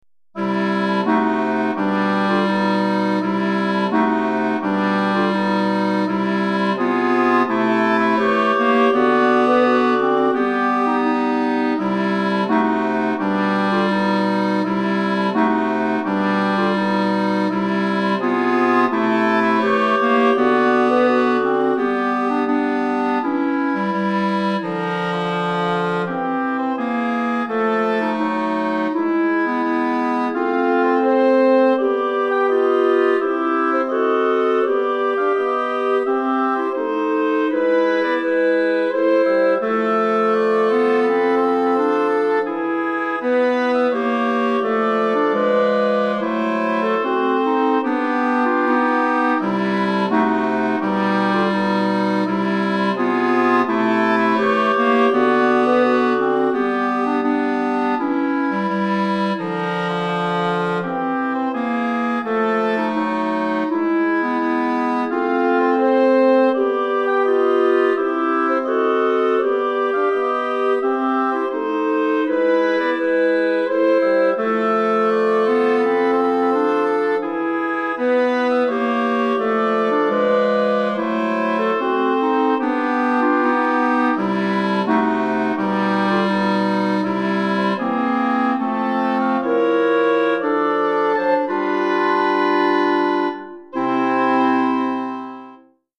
Quatuor à Vents